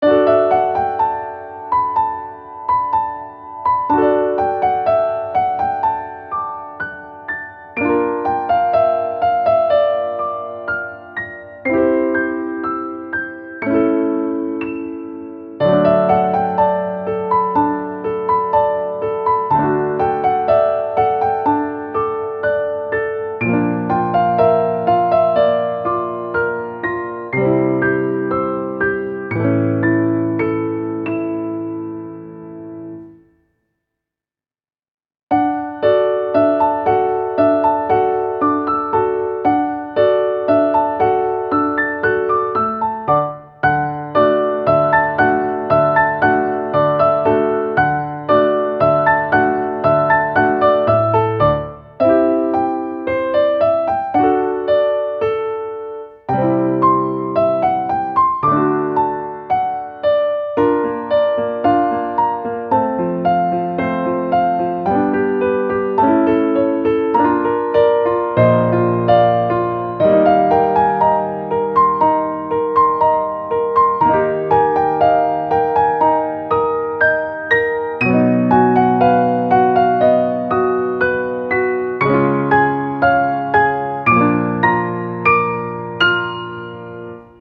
• 明るくほがらかなピアノ曲のフリー音源を公開しています。
ogg(L) - さわやか 綺麗 早朝 始まり